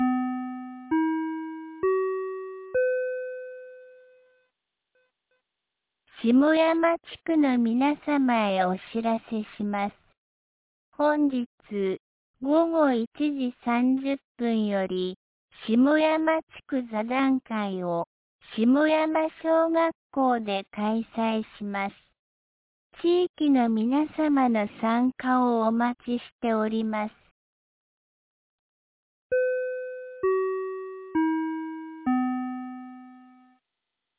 2025年07月10日 12時53分に、安芸市より下山へ放送がありました。